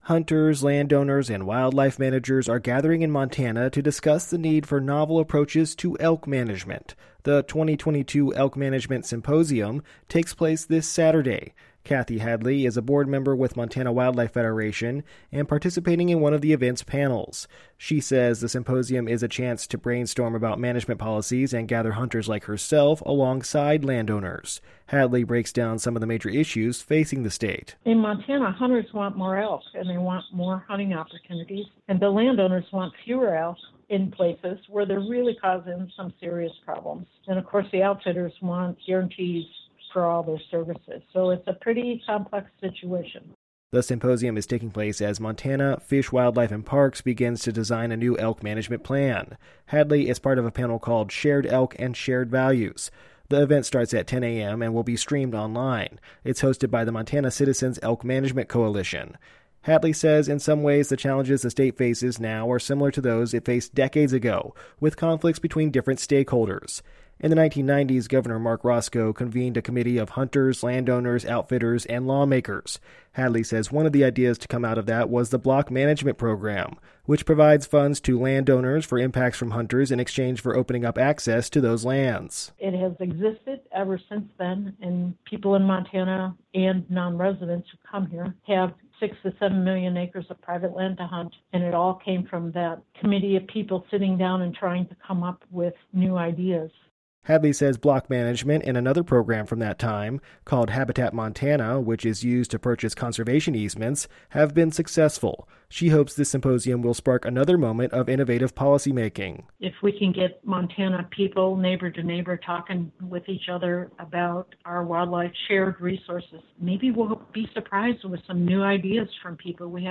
(pronouncer: Racicot is "roscoe")